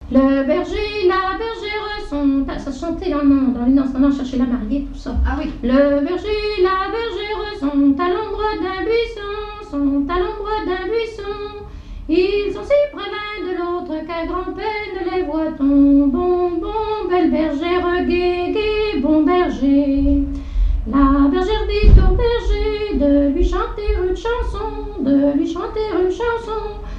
Genre strophique
airs de danses et chansons traditionnelles
Pièce musicale inédite